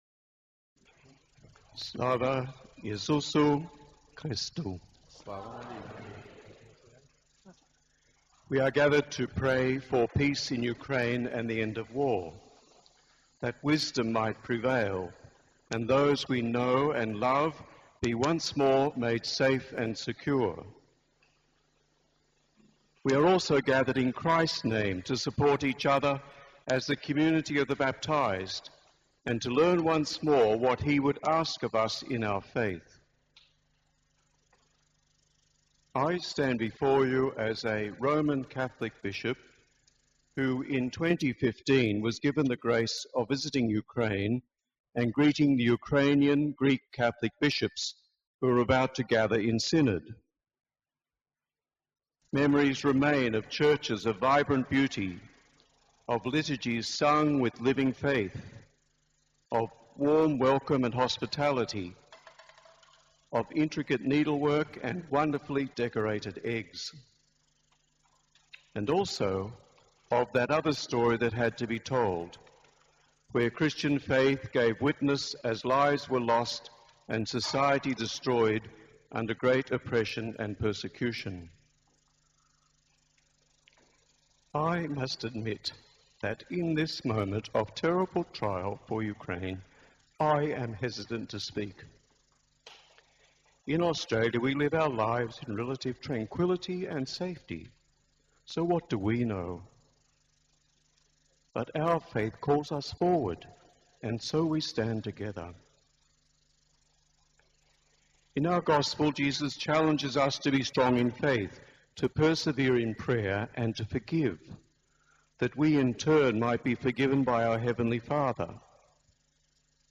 Bishop Terry Curtin in Ukrainian Catholic Cathedral of the Holy Apostles Peter and Paul.
Prayer for peace in Ukraine.